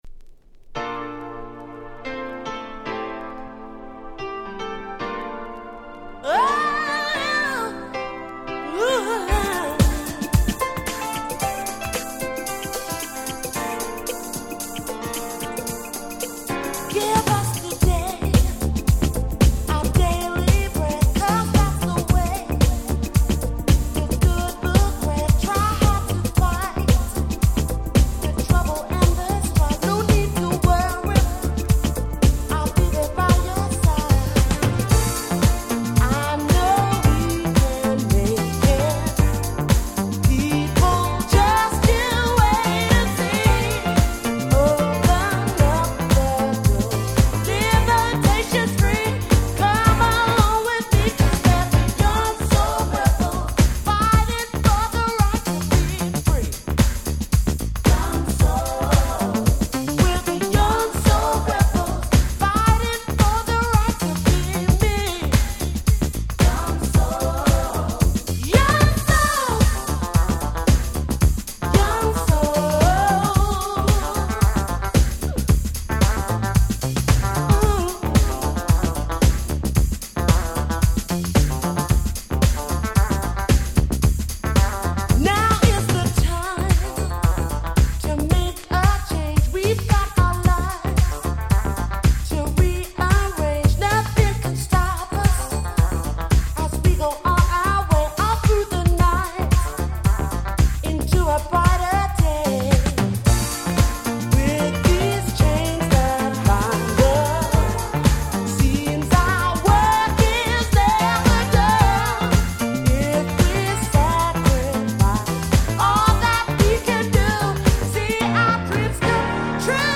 UK R&B Classics !!